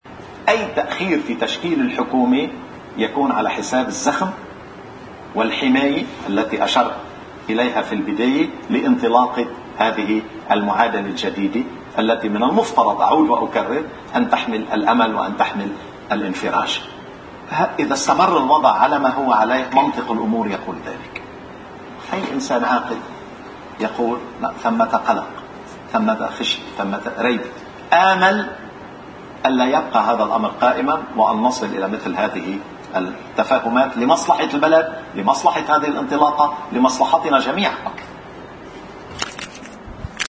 أمل الوزير غازي العريضي ضمن برنامج “نهاركم سعيد” على شاشة الـ“LBC” أن تشكّل الحكومة الجديدة فور إنتخاب رئيس للجمهورية، لنحافظ على زخم العهد الجديدي، وقال: